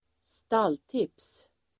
Ladda ner uttalet
Uttal: [²st'al:tip:s]